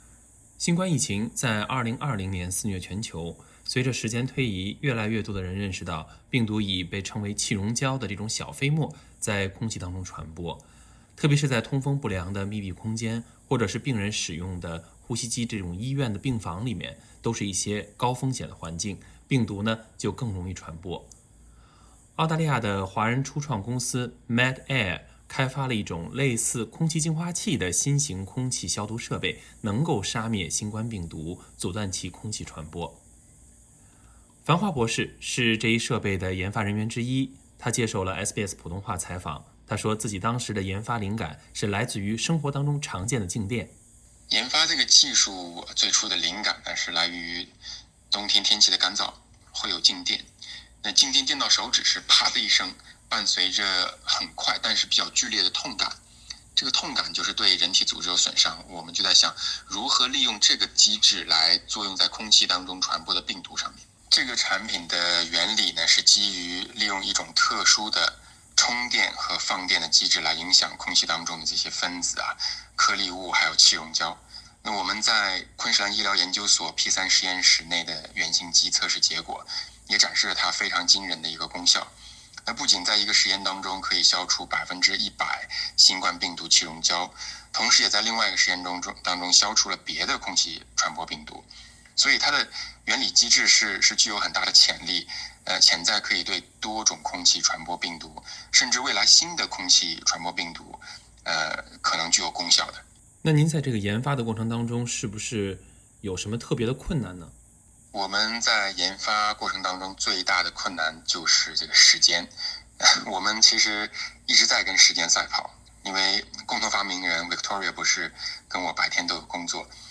點擊收聽詳細寀訪音頻。